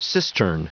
Prononciation du mot cistern en anglais (fichier audio)
Prononciation du mot : cistern